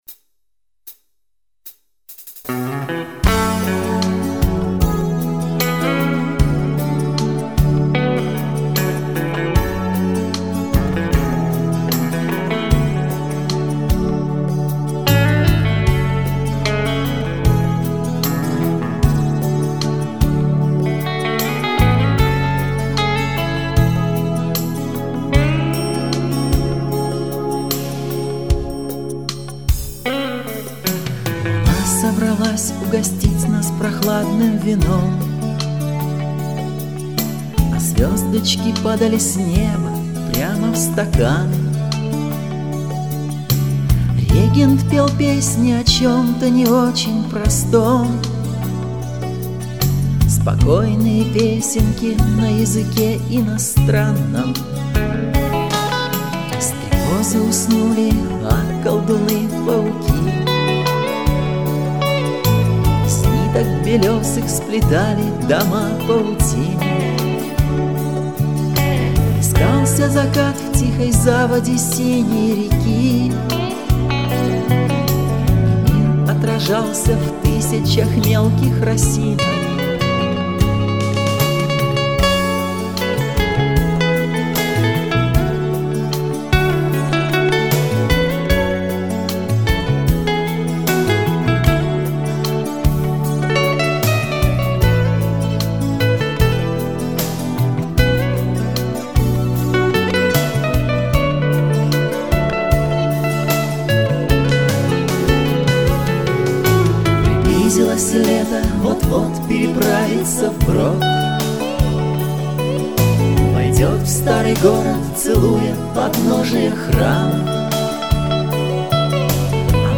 Есть в альбоме и Рок`н`Ролл
вокал, бэк вокал, акустические гитары